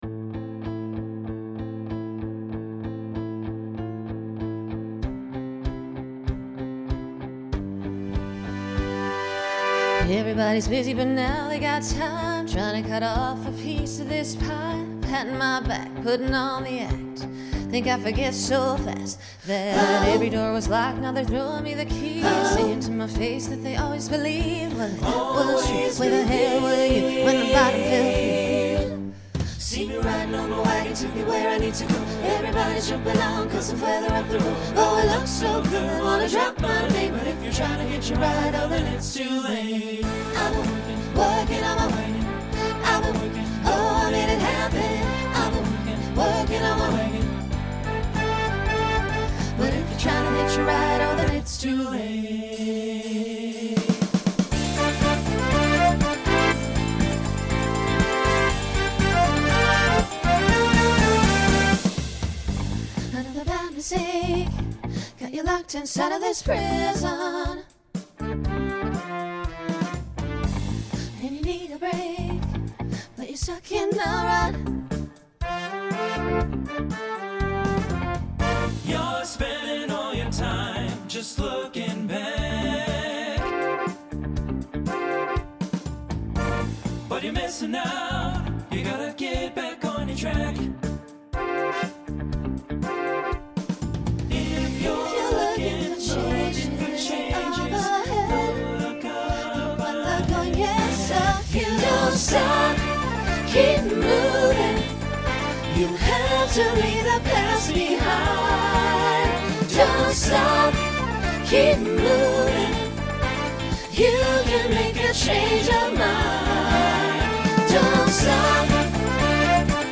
Broadway/Film , Pop/Dance , Rock Instrumental combo
Voicing SATB